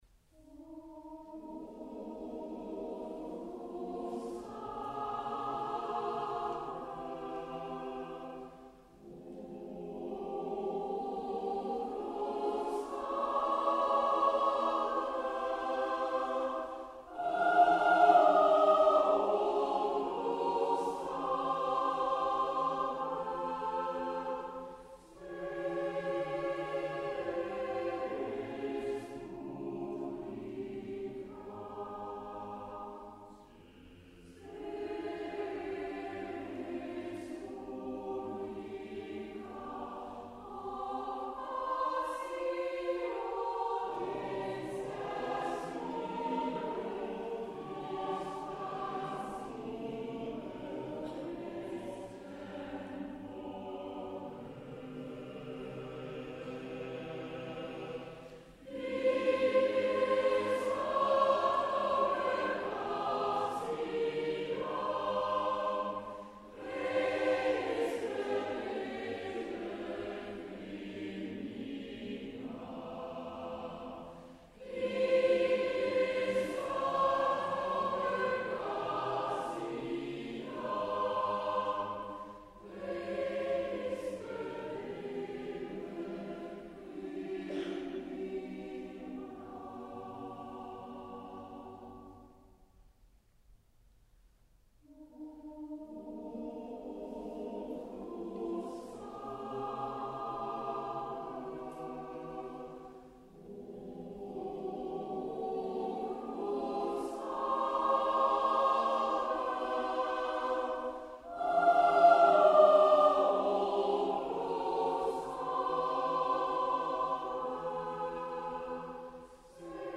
Hörbeispiele der Kantorei